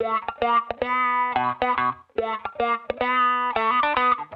Index of /musicradar/sampled-funk-soul-samples/110bpm/Guitar
SSF_StratGuitarProc2_110B.wav